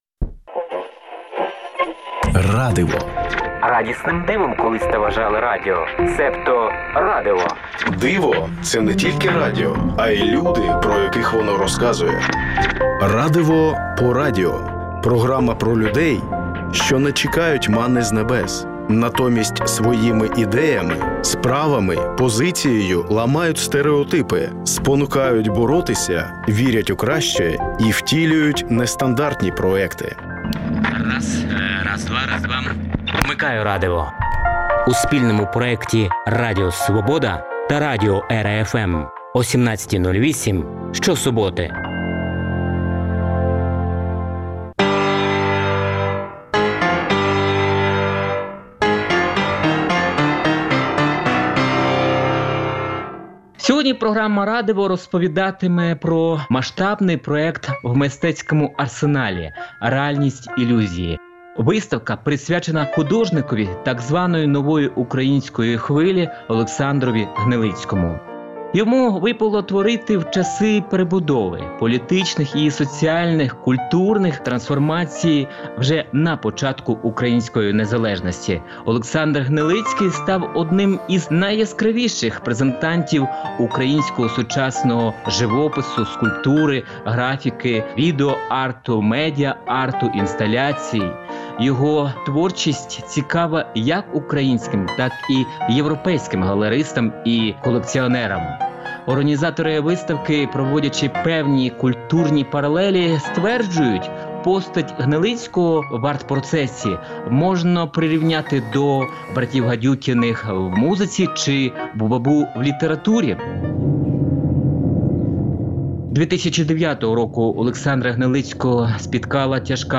«Радиво» – програма про незвичайних-звичайних українців. Звучить щосуботи о 17:08 в ефірі радіо «Ера-FM» та в аудіоподкастах на сайті Радіо Свобода.